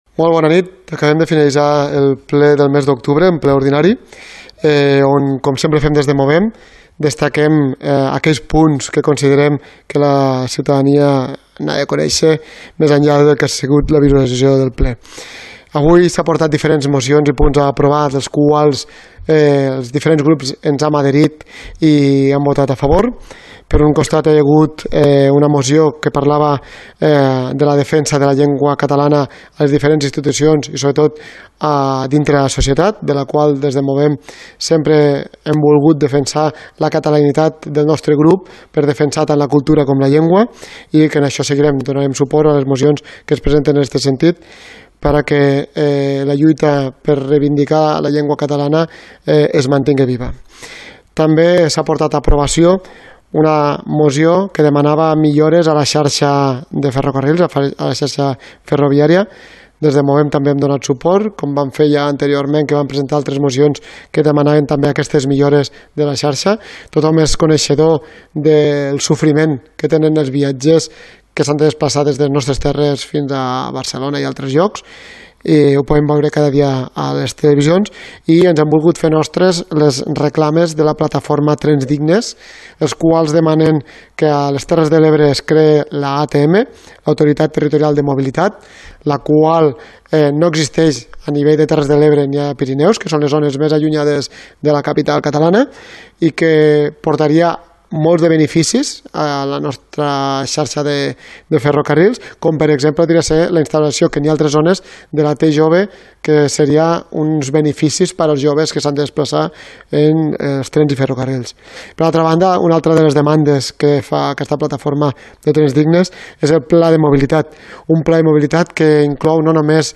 Ple Ordinari de Roquetes – Octubre 2029 – Declaracions – Movem Roquetes – David Poy | Antena Caro - Roquetes comunicació